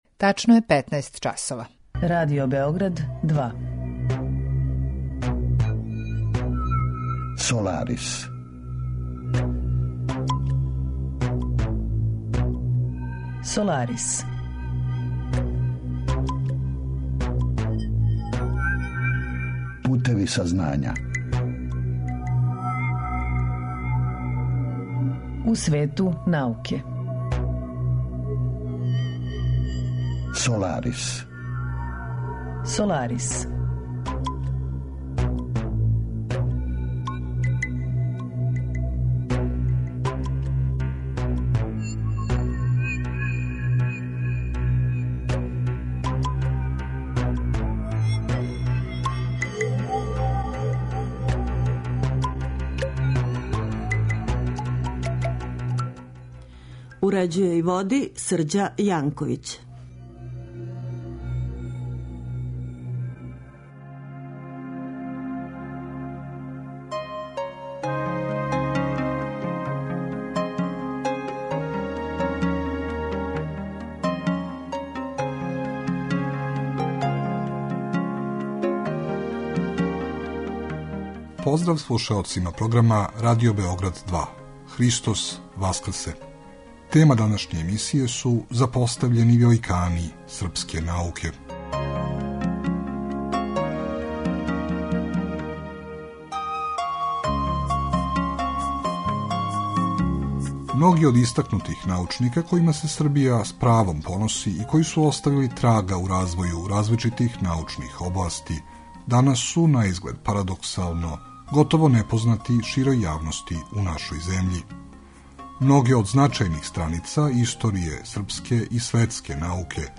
Разговор је први пут емитован 3. августа 2016.